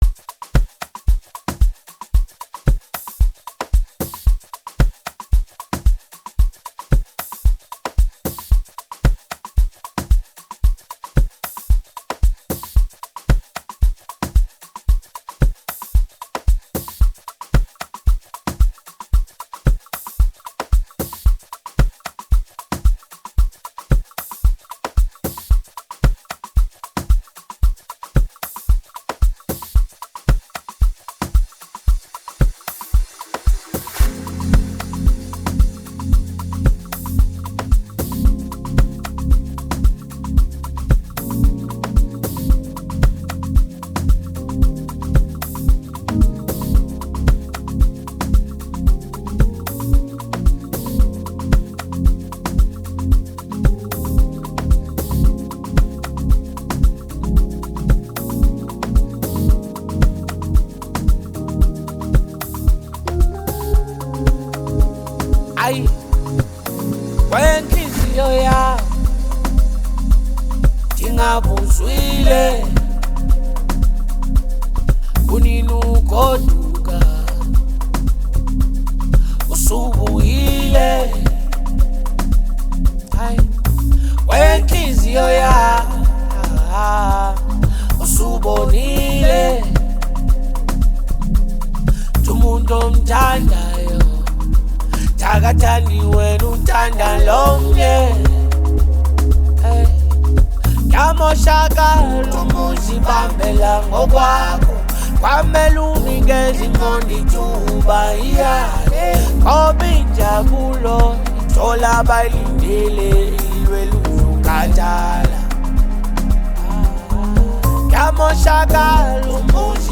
Amapiano song
smooth vocals and the catchy hook
blends Afrobeat with contemporary sounds
With its upbeat Amapino tempo and catchy sounds